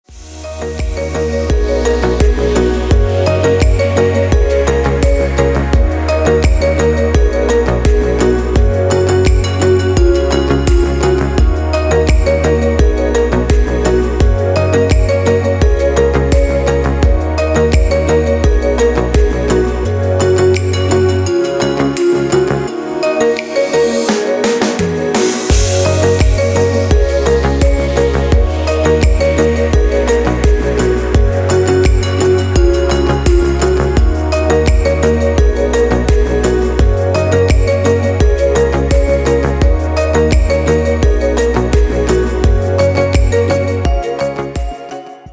красивые
dance
спокойные
без слов
tropical house